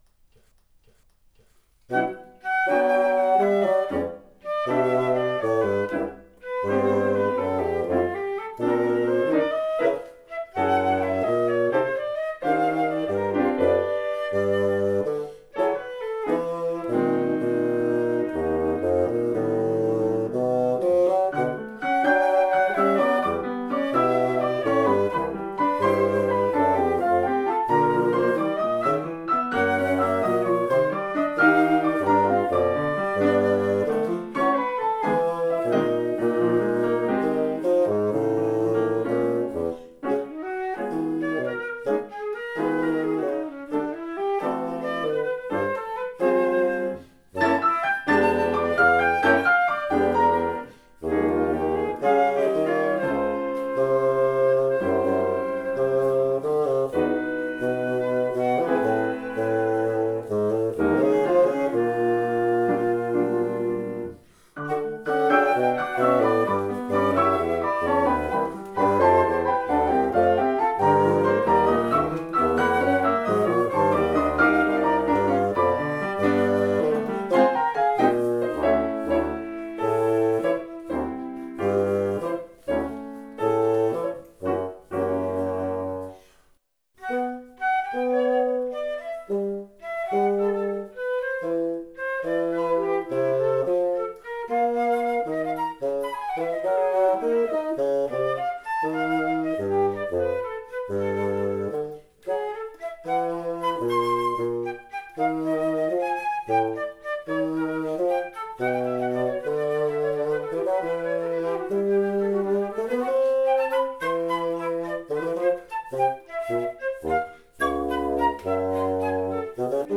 J'ai fait une infidélité aux cordes, en m'insérant dans un trio avec vents, ici dans une petite pièce contemporaine :
flûte
basson
piano